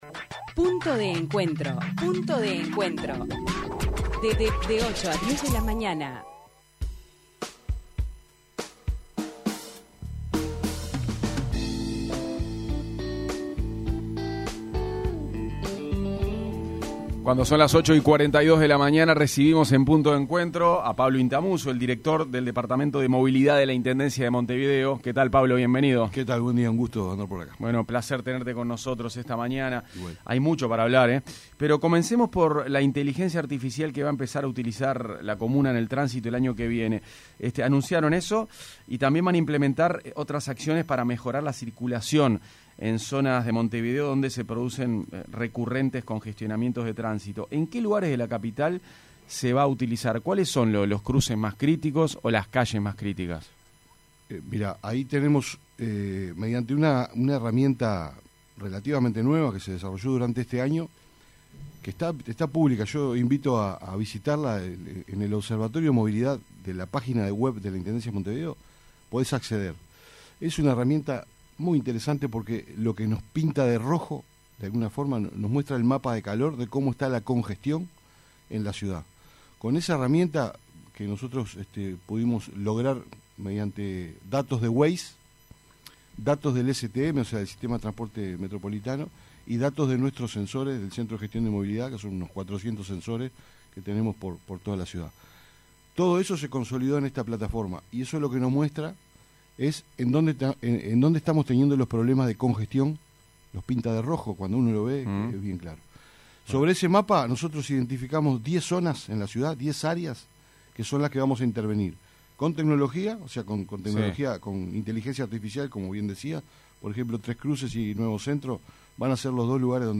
El Director de Movilidad de la Intendencia de Montevideo, Pablo Inthamoussu, fue entrevistado en Punto de Encuentro en 970 Universal, sobre los cambios en cambios en tránsito anunciados por la IMM respecto al flechamiento de 21 de Setiembre hacia la rambla. Además también se refirió sobre las acciones para mejorar los puntos que presentan congestionamiento vehícular en Montevideo.